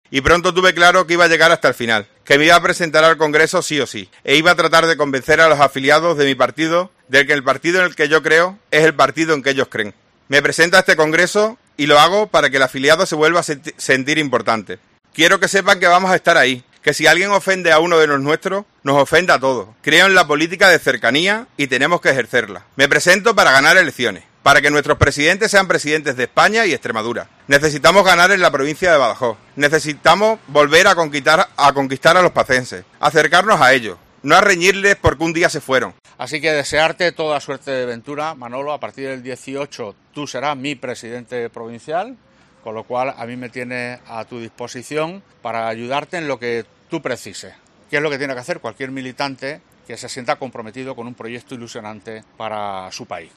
Manuel Naharro y José Antonio Monago. Presentación único candidato XIV Congreso Prov PP Badajoz